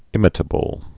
(ĭmĭ-tə-bəl)